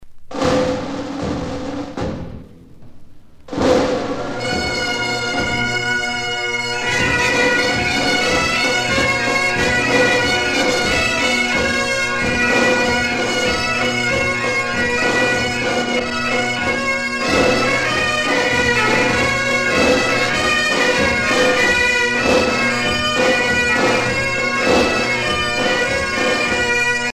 danse : an dro
Pièce musicale éditée